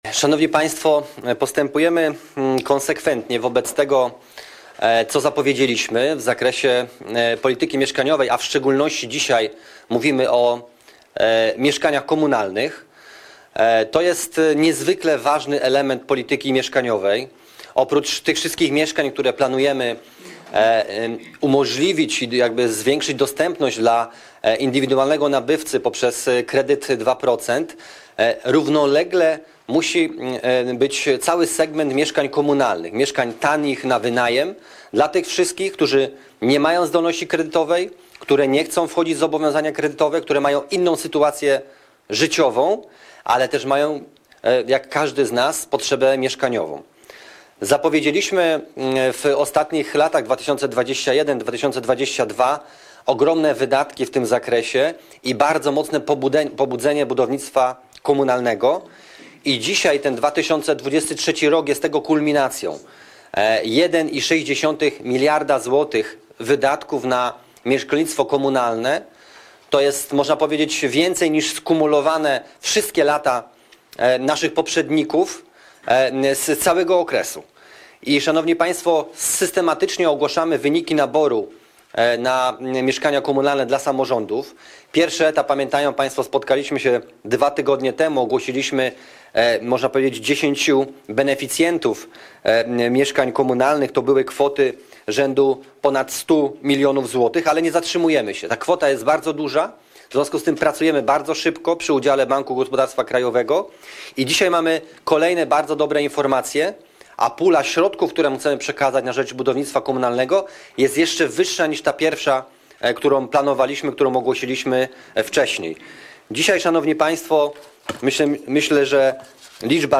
Zapis audio konferencji prasowej min. Waldemara Budy 28.02.2023